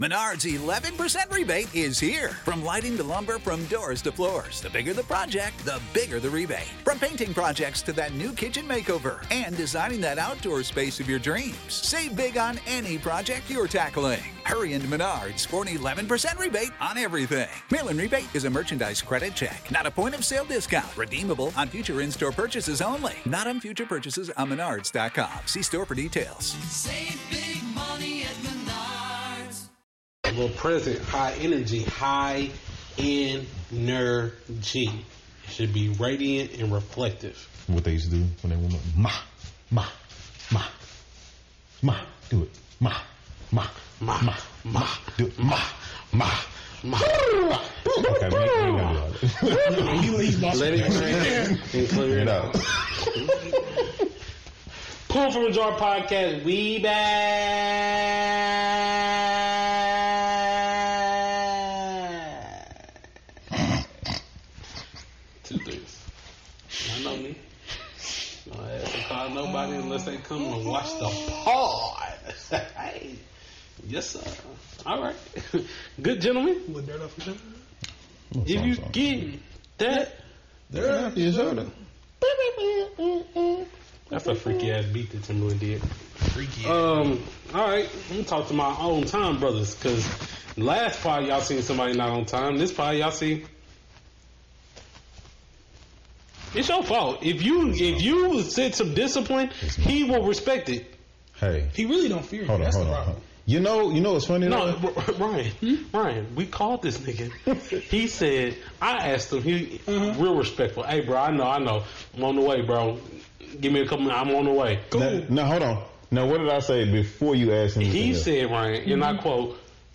The #1 Source for all opinionated, non-factual, entertaining, hilarious takes.